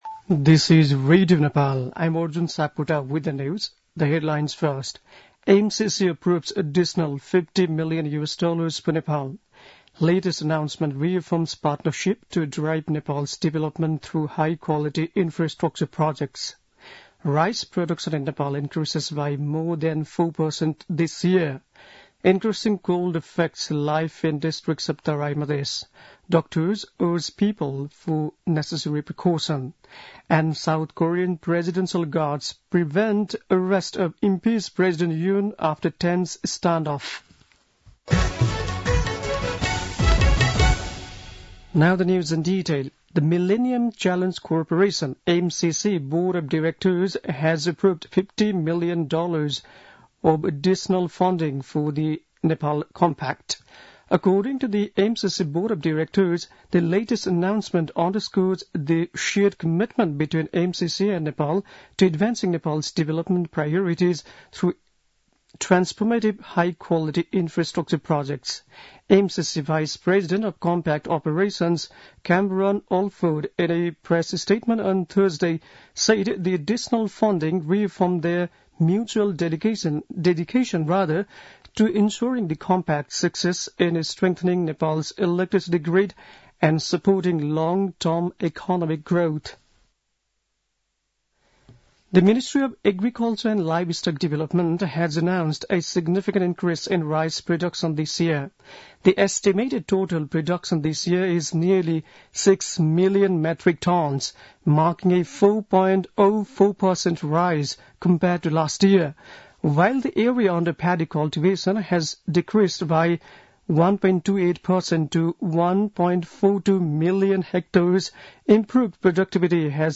बेलुकी ८ बजेको अङ्ग्रेजी समाचार : २० पुष , २०८१
8-pm-english-news-9-19.mp3